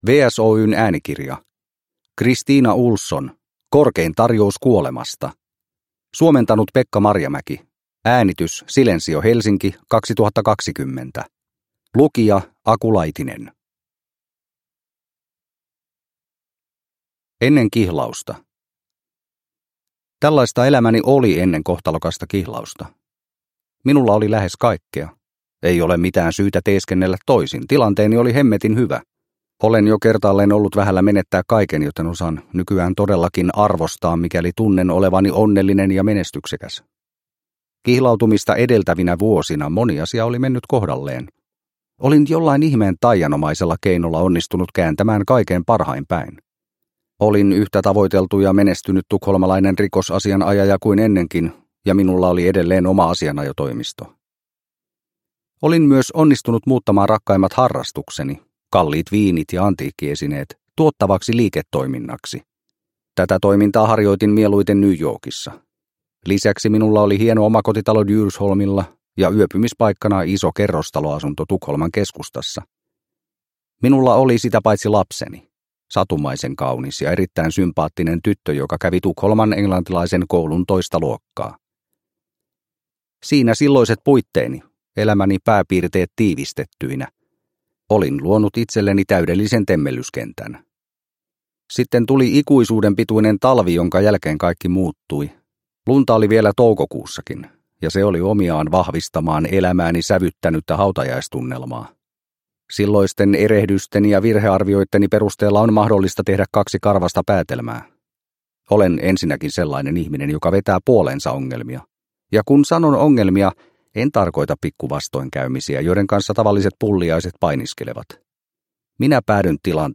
Korkein tarjous kuolemasta – Ljudbok – Laddas ner